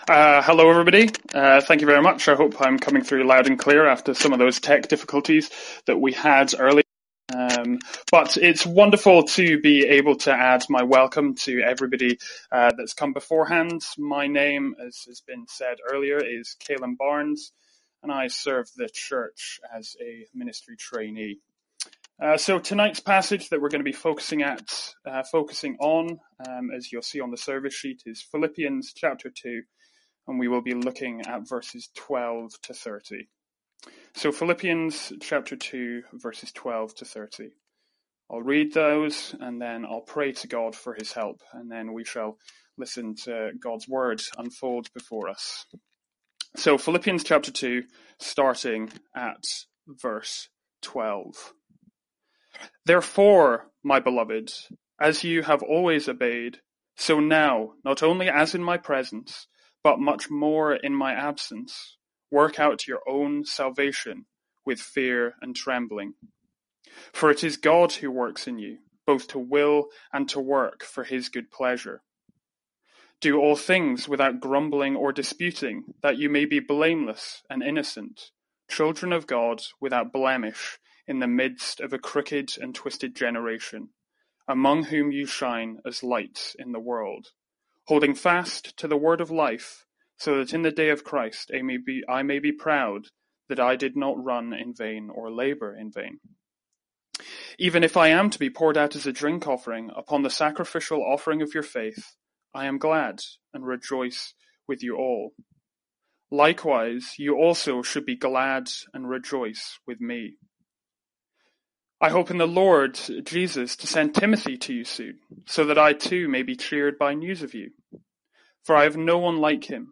Sermons | St Andrews Free Church
From our evening service in Philippians.